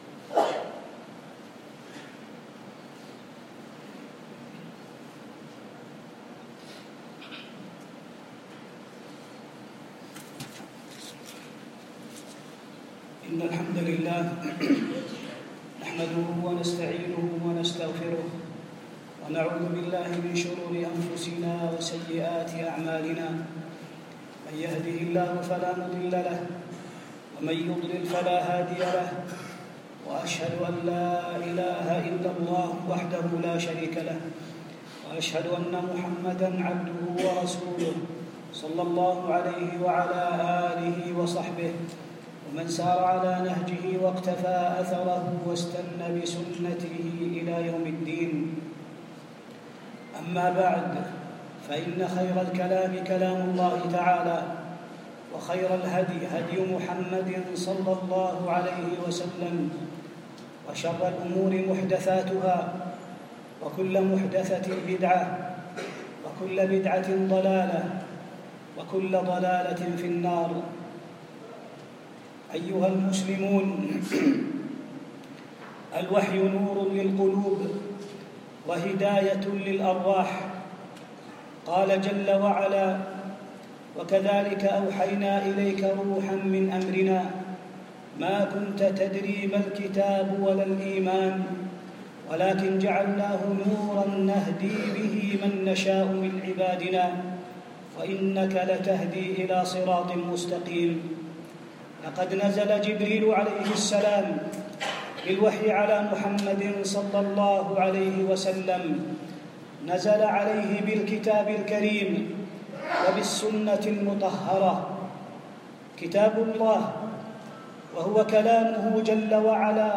مسجد الإمام عبد العزيز بن باز، بالجامعة الإسلامية بالمدينة النبوية
جهود أئمة الحديث في حفظ الدين - خطبة